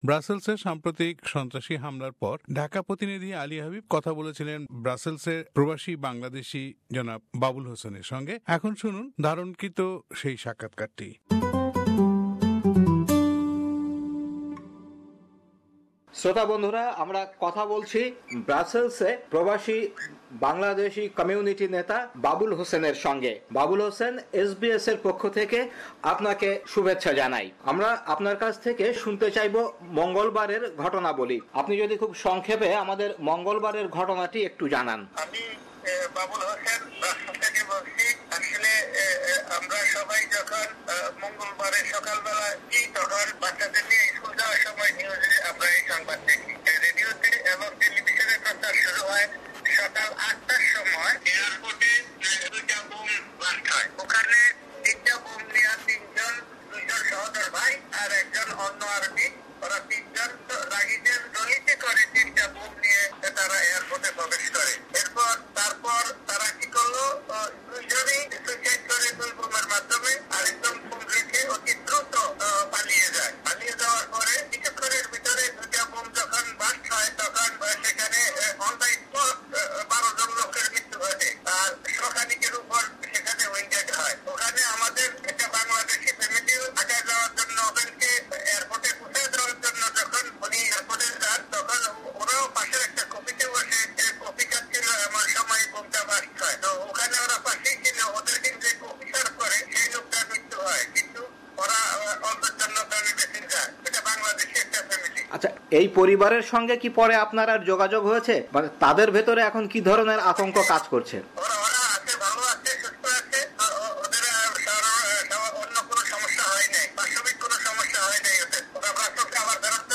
Brussel Terror Attack : Interview with a Bangladeshi resident at Brussels